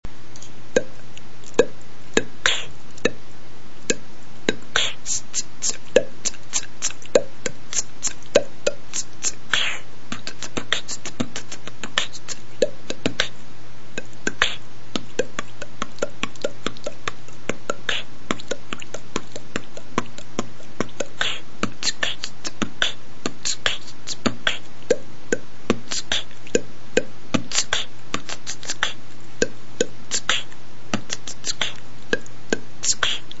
Форум российского битбокс портала » Реорганизация форума - РЕСТАВРАЦИЯ » Выкладываем видео / аудио с битбоксом » Оцените
Вот только выучил драй кик, решил попробывать не судите строго)
на бульк похоже biggrin прикольно happy
Да кста когда быстро делаешь, бульк получается , звучит прикольно )